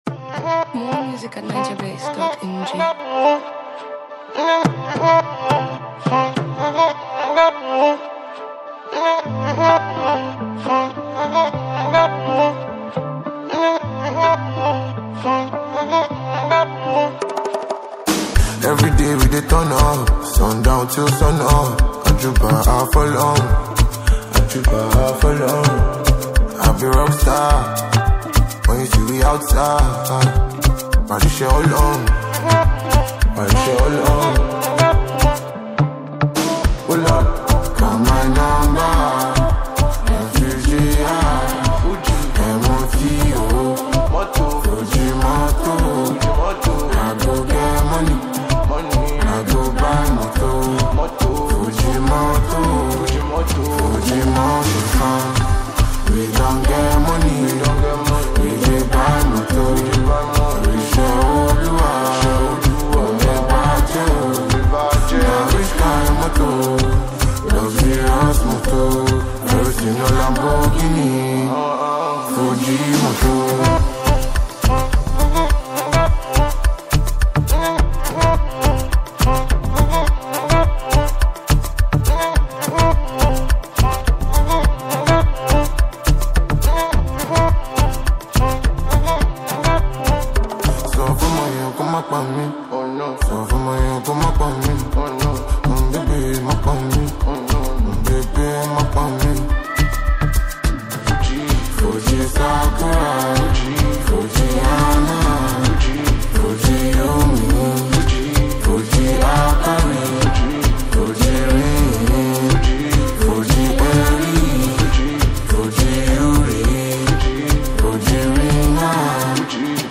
vibrant and street-inspired track
With catchy rhythms and relatable lyrics